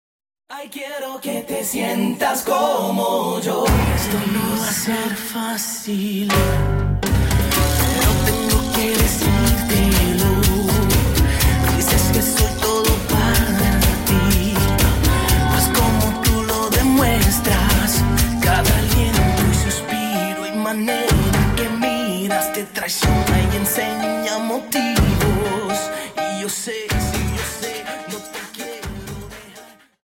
Cha Cha 31 Song